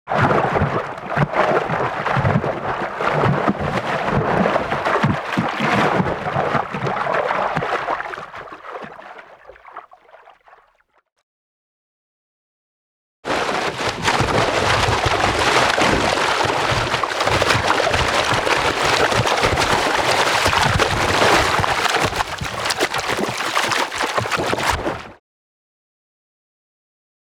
Shark Attack Sound
animal